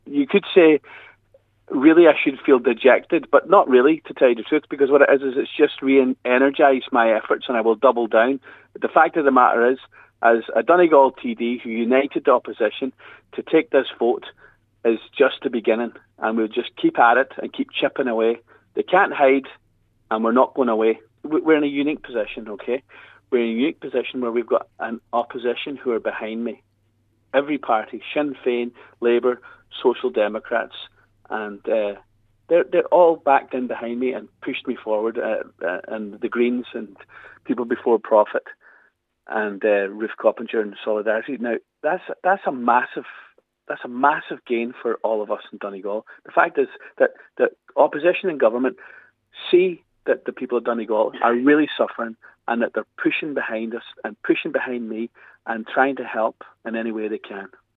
Deputy Charles Ward says, despite the outcome, he is not feeling defeated today: